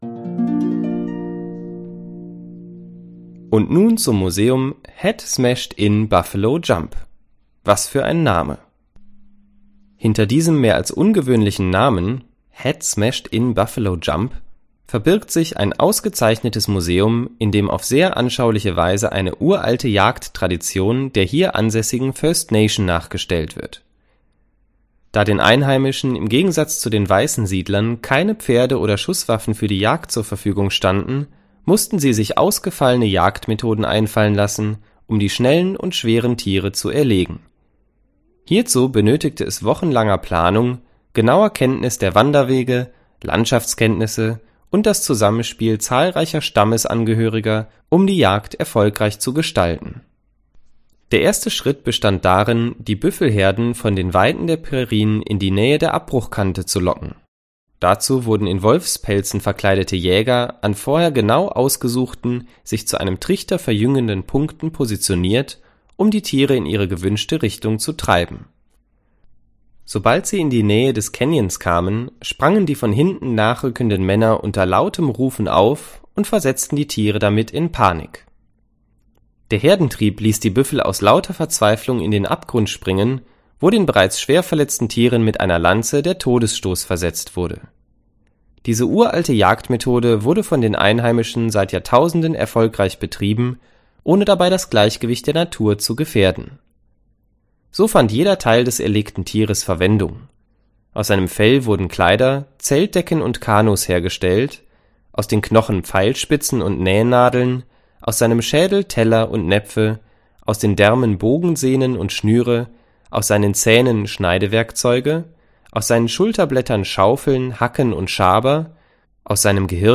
Die Informationen wurden von erfahrenen Reiseleitern zusammengetragen und professionell eingesprochen.
Hoerbeispiel-Head-Smashed-In-Buffalo-Jump.mp3